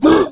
Index of /App/sound/monster/orc_black
attack_1.wav